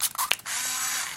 相机 " 美能达V300 Zoom1
描述：放大镜头/聚焦于Minolta Vectis300 APS胶片相机。这个系列有几种不同的声音，一些点击，一些变焦噪音。第四代iPod touch，使用media.io转换并使用Audacity编辑。
标签： VECTIS-300 美能达 VECTIS 照相机 变焦 胶片相机 聚焦 摄影 美能达-VECTIS APS 伺服 照片
声道立体声